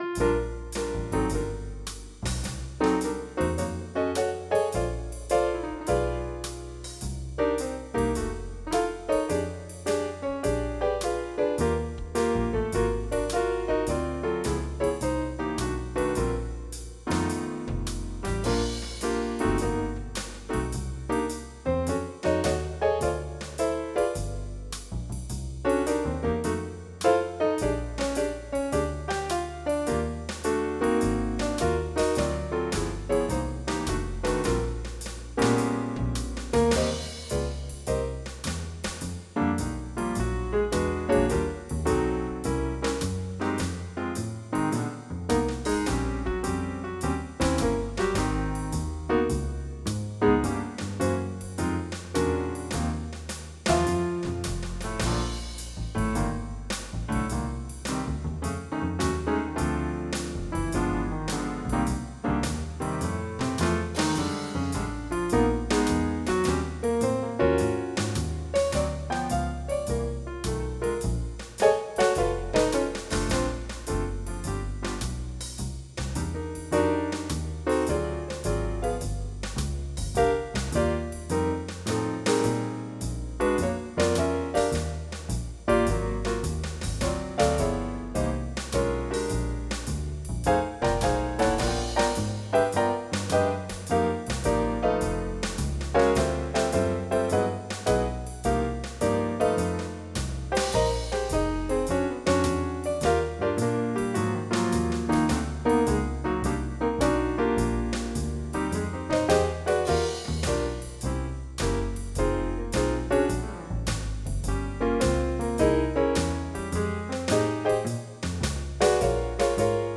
Jazz
MIDI Music File